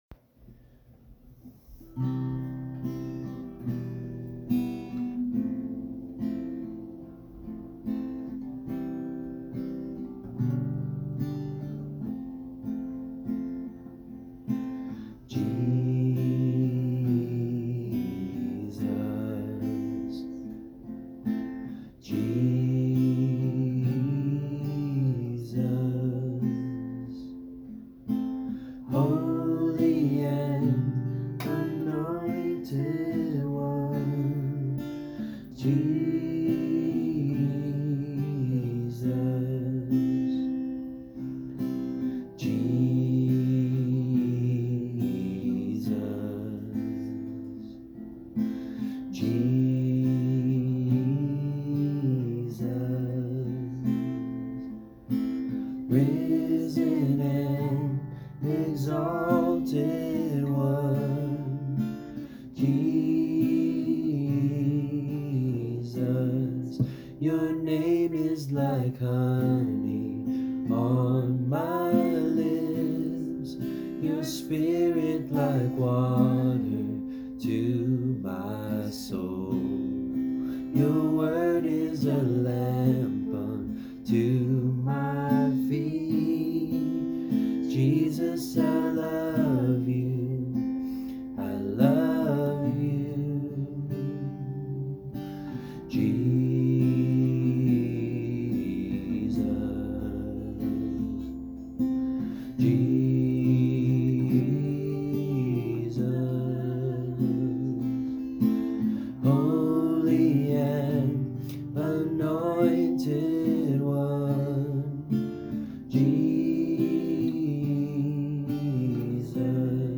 1. Sunday Worship – First Song: